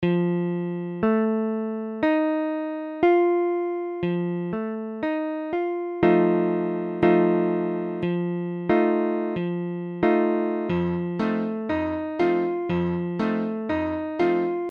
Tablature F7.abcF7 : accord de Fa septième
Mesure : 4/4
Tempo : 1/4=60
A la guitare, on réalise souvent les accords en plaçant la tierce à l'octave.